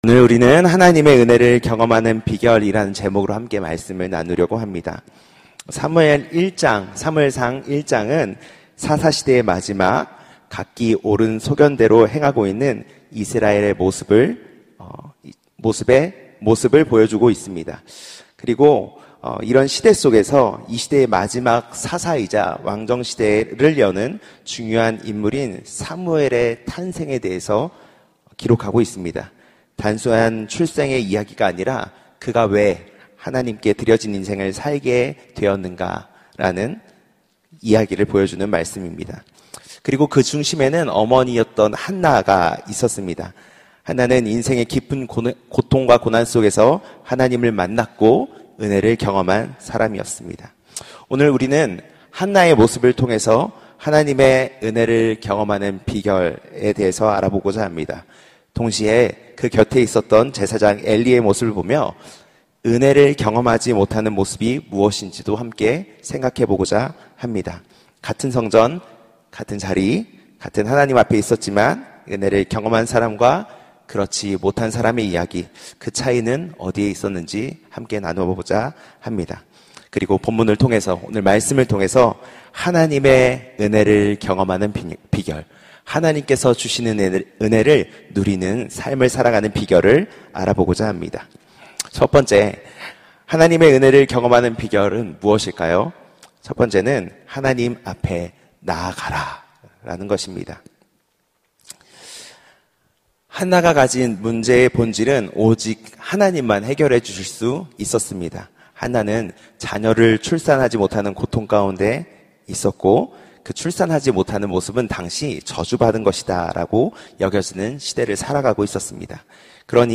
설교 : 파워웬즈데이 은혜를 경험하는 비결 설교본문 : 사무엘상 1:9-18 설교자